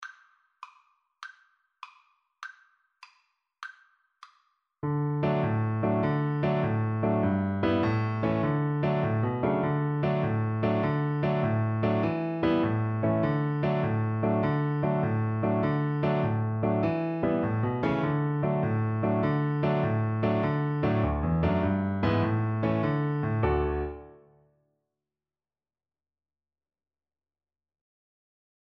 Quick two in a bar . = c.100
D major (Sounding Pitch) (View more D major Music for Viola )
6/8 (View more 6/8 Music)